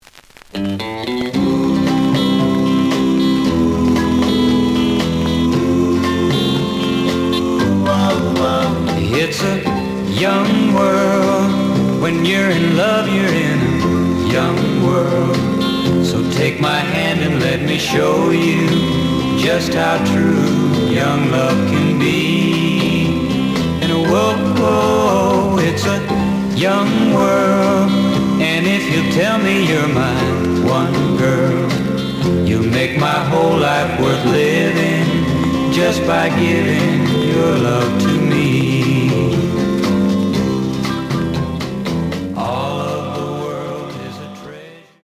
試聴は実際のレコードから録音しています。
●Genre: Rhythm And Blues / Rock 'n' Roll
●Record Grading: VG- (両面のラベルにダメージ。傷は多いが、プレイはまずまず。ジャケットなし。)